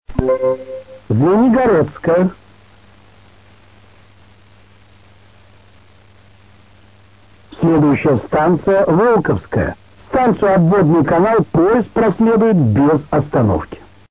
Еще пару праздничных объявлений в метро: